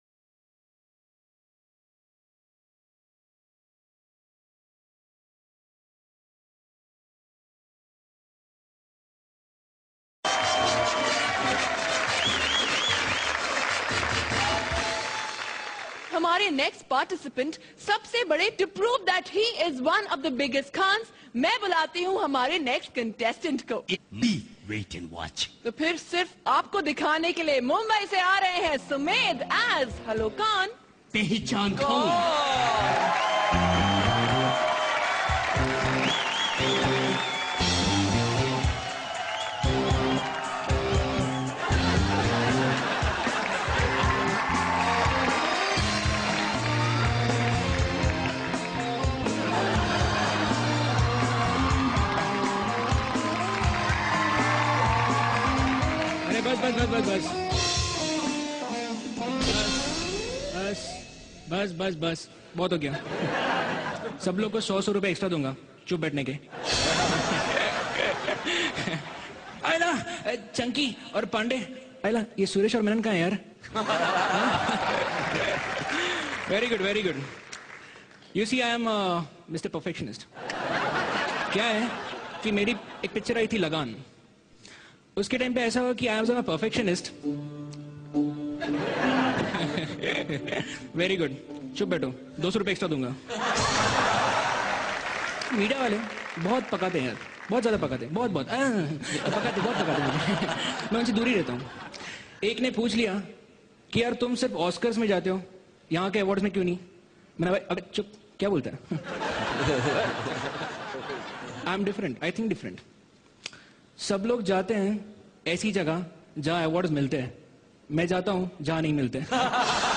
HomeMp3 Audio Songs > Whatsapp Audios > Funny Mimicry Voice